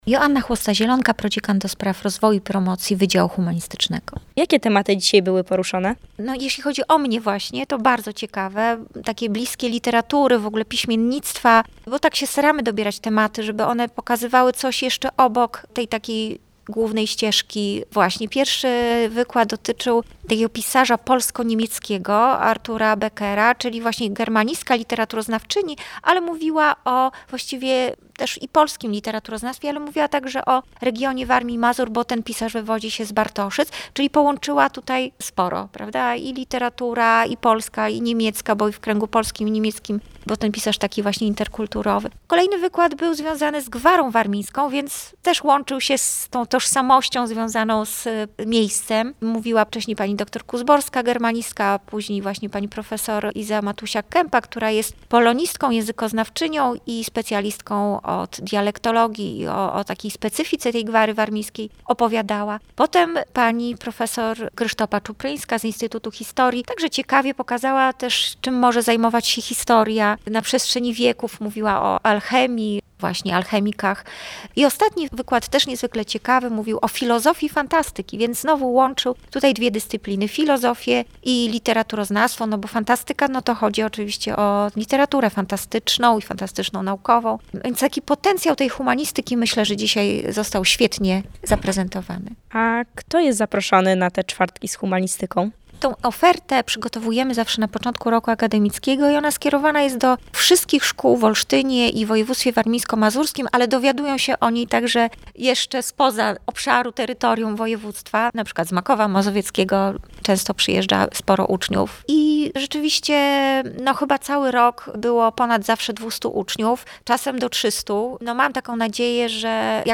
Było to już przedostatnie w tym roku akademickim spotkanie uczniów z regionu z wykładowcami Wydziału Humanistycznego Uniwersytetu Warmińsko-Mazurskiego w Olsztynie. 20 marca podczas czterech wykładów mogli lepiej poznać pochodzącego z Bartoszyc polsko-niemieckiego pisarza, prozaika, eseistę i tłumacza – Artura Beckera – ale też posłuchać ciekawostek na temat gwary warmińskiej w literaturze, filozofii fantastyki oraz pracy… astrologa i alchemika. Posłuchajcie relacji naszej reporterki!